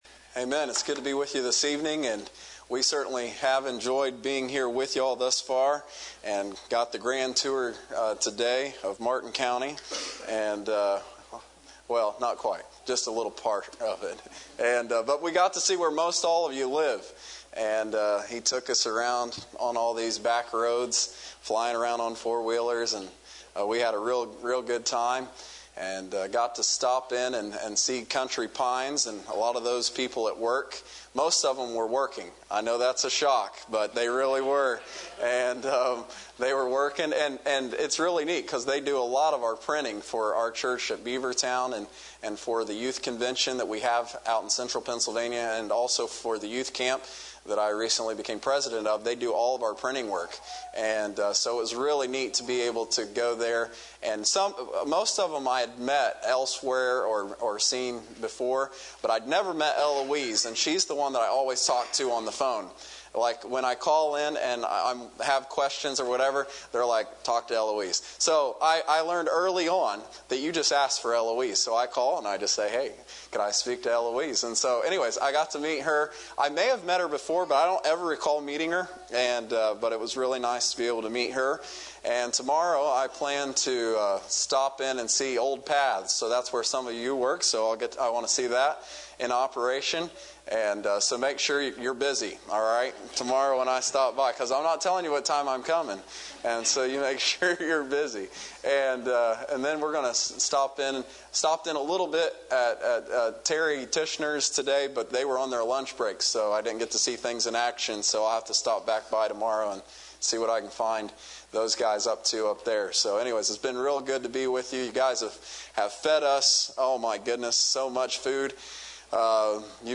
Series: Youth Revival 2016